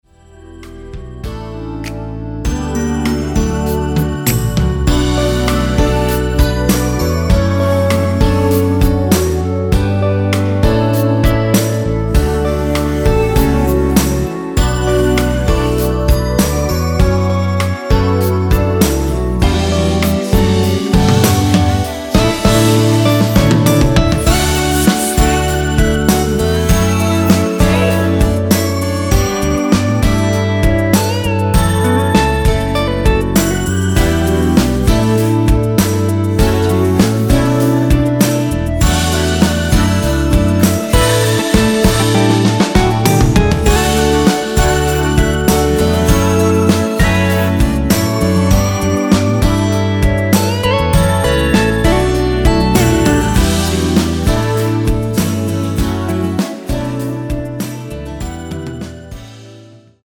원키 멜로디와 코러스 포함된 MR입니다.(미리듣기 참조)
앞부분30초, 뒷부분30초씩 편집해서 올려 드리고 있습니다.